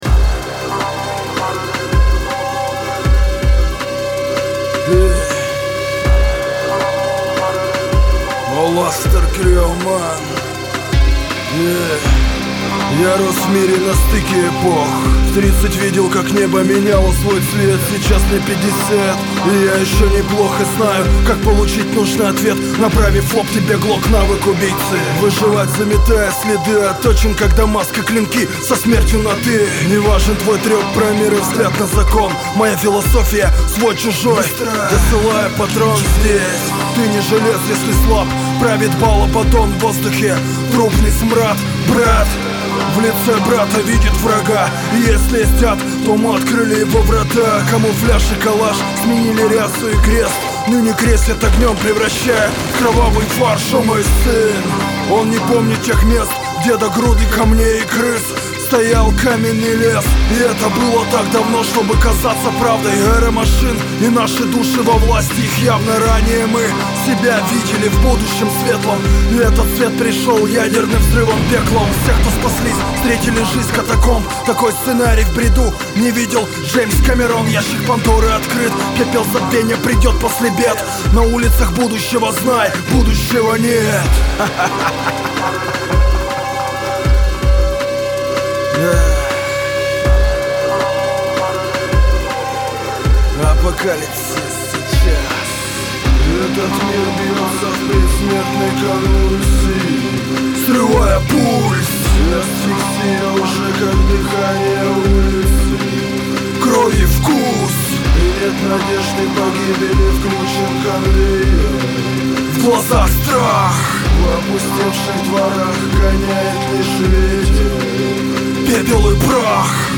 гитары
Крутой и мрачный трэк, надеюсь судьи и участники заценят.